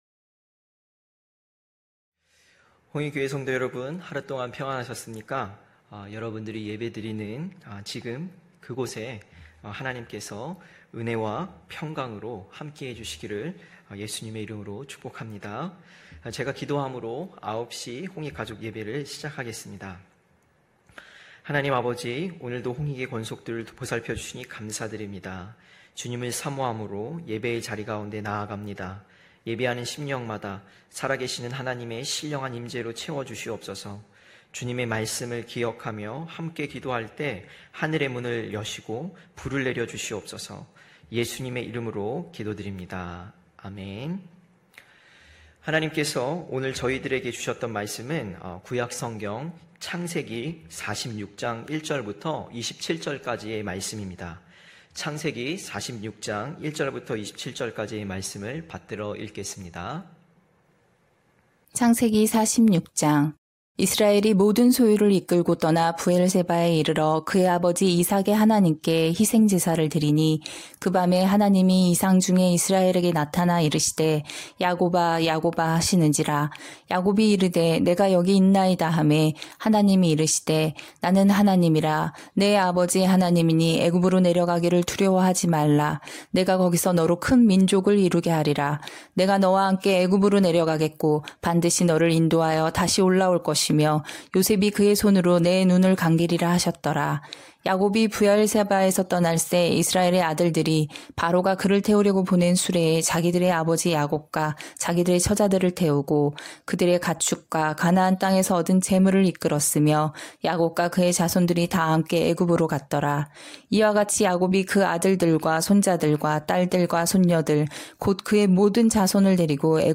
9시홍익가족예배(9월17일).mp3